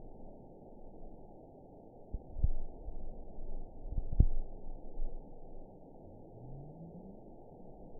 event 920280 date 03/11/24 time 09:29:48 GMT (1 year, 2 months ago) score 9.57 location TSS-AB01 detected by nrw target species NRW annotations +NRW Spectrogram: Frequency (kHz) vs. Time (s) audio not available .wav